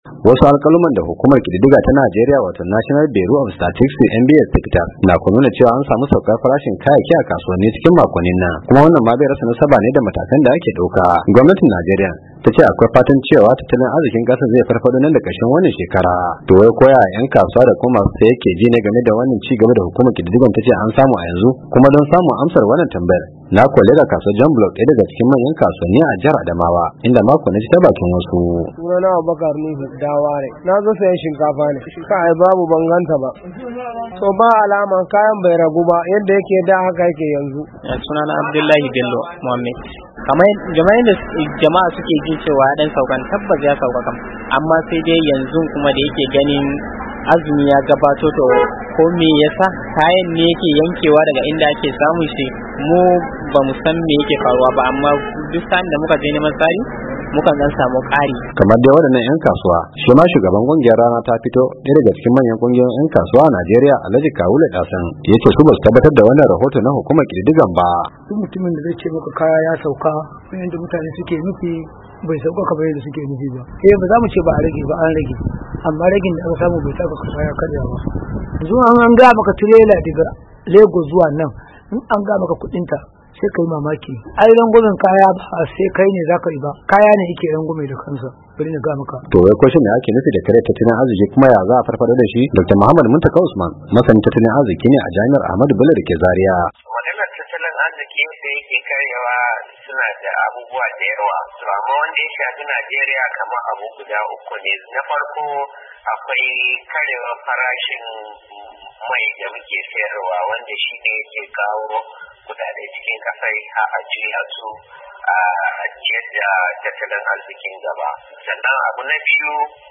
ya leka jan Block ‘daya daga cikin manyan kasuwanni a jihar Adamawa.